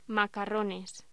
Locución: Macarrones
voz